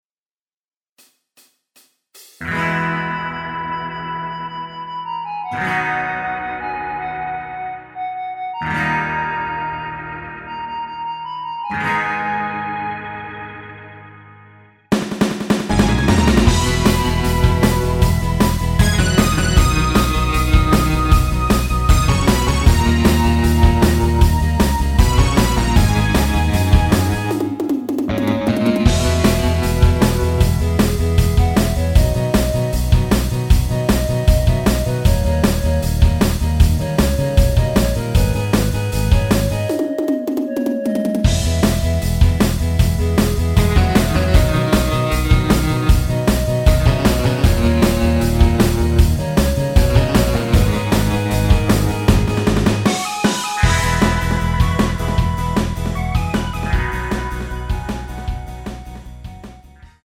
원곡의 보컬 목소리를 MR에 약하게 넣어서 제작한 MR이며